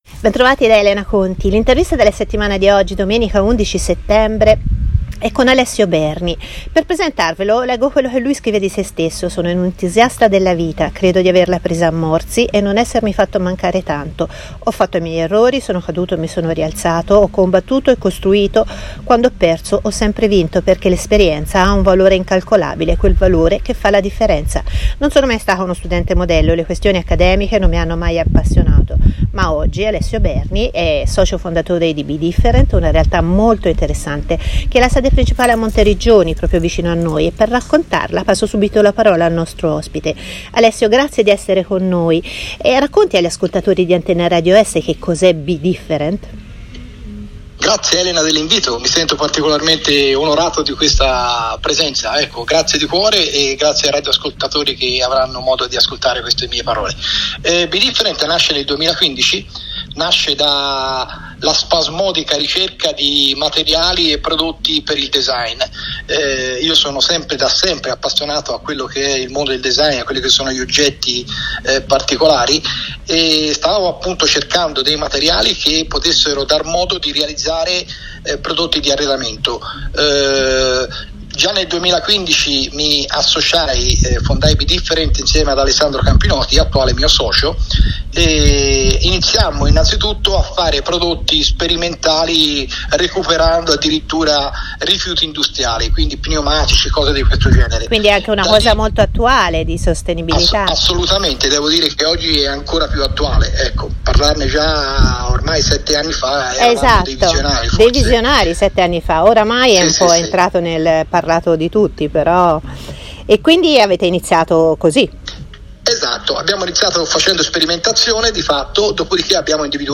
Intervista della Settimana